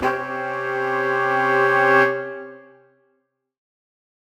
Index of /musicradar/undercover-samples/Horn Swells/C
UC_HornSwell_Cmin9.wav